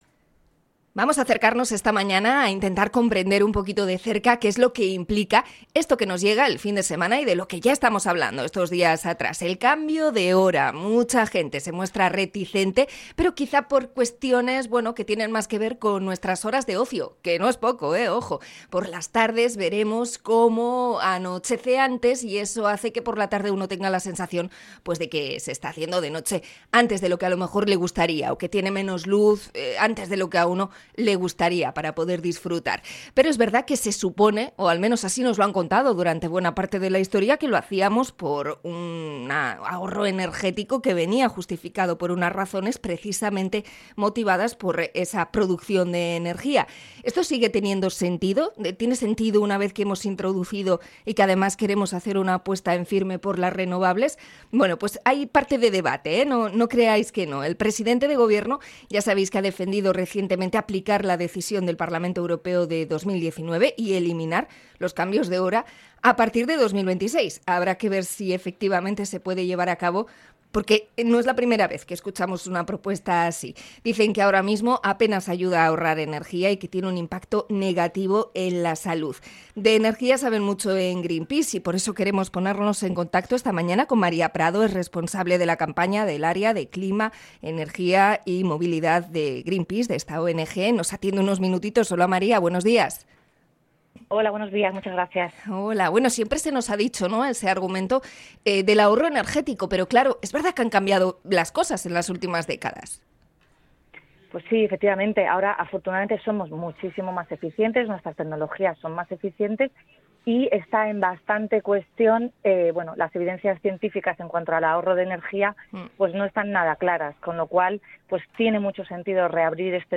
Entrevista a Greenpeace por el cambo de hora